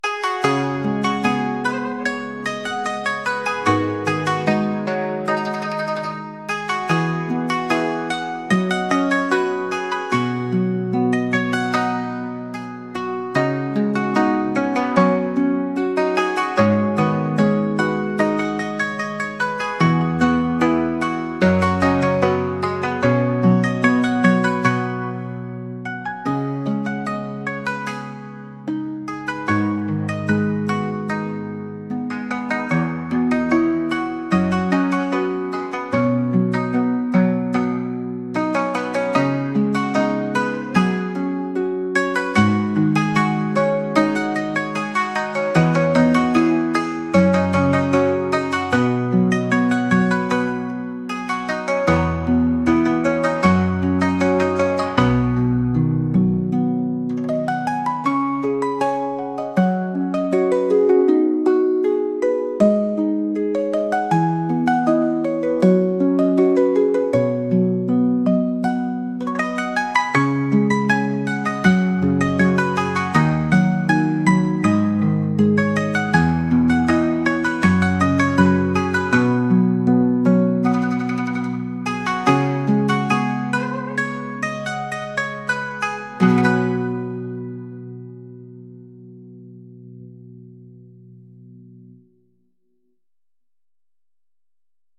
日常をのんびり過ごすような中華っぽい曲です。